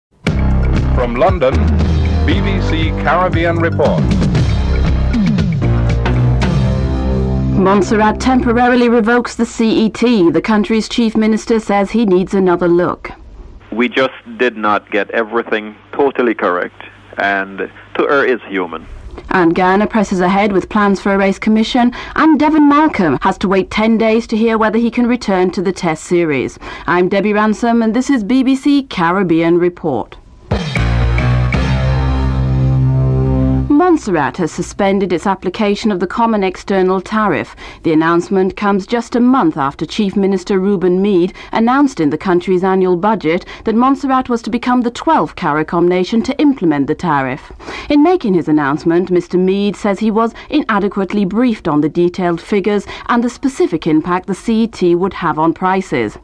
7. Wrap up and theme music (14:44-14:55)